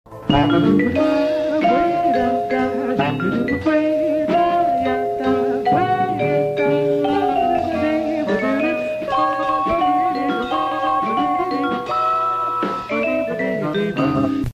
BriefScattingJazz.mp3